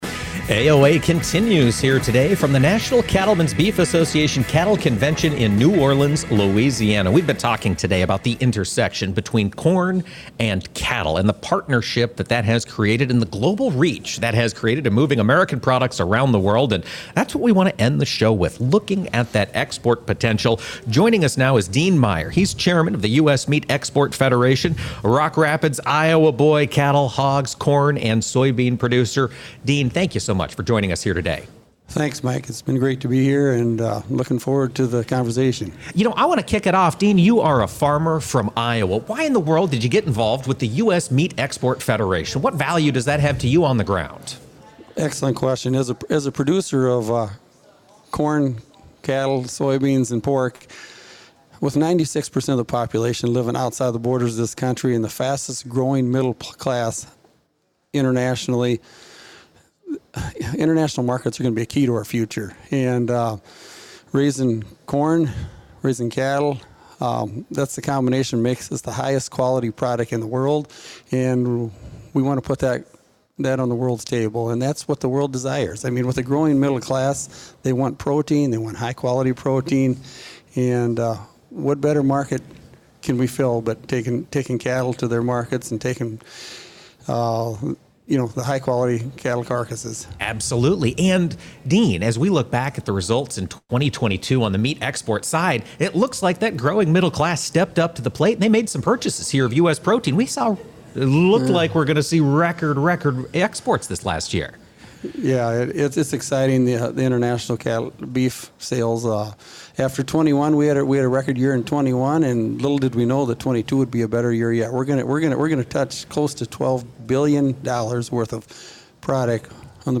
At the Cattle Industry Annual Convention in New Orleans, the National Corn Growers Association led efforts to educate attendees on the important relationship between the U.S. corn and cattle industries. This included an edition of Agriculture of America, which was broadcast live from the convention.